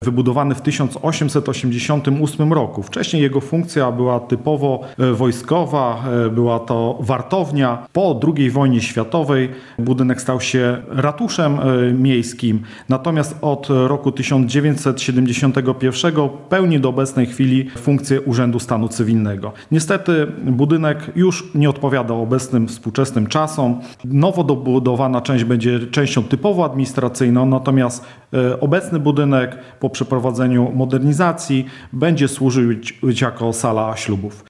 – Budynek znajduje się pod opieką konserwatora zabytków – mówi w rozmowie z Radiem Lublin burmistrz Łęcznej Leszek Włodarski.